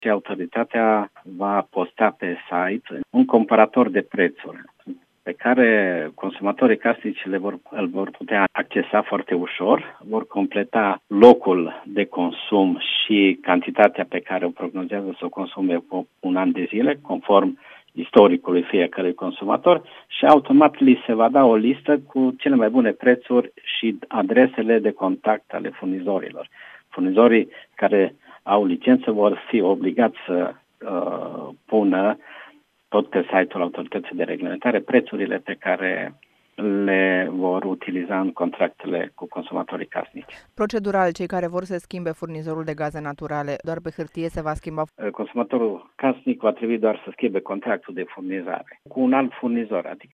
Am stat de vorbă cu Niculae Havrileț, președintele ANRE.